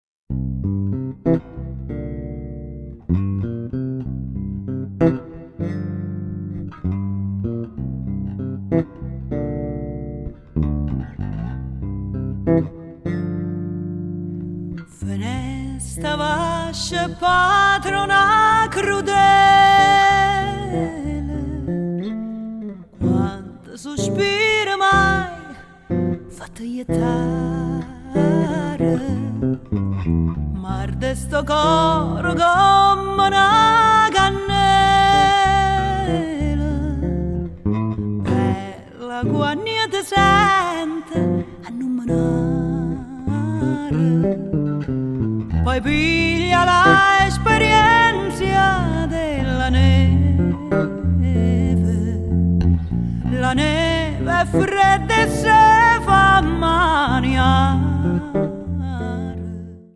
vocals
electric basses, stick, midi
percussion
violin
viola
violoncello